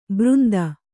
♪ břnda